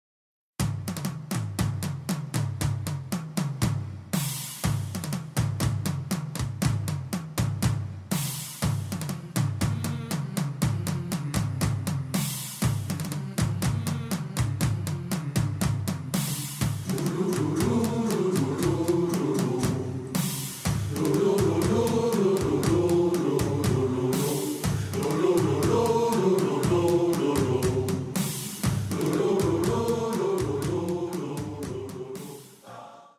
Notre choeur
Extraits de notre répertoire